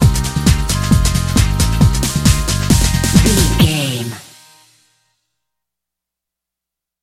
Ionian/Major
Fast
synthesiser
drum machine
Eurodance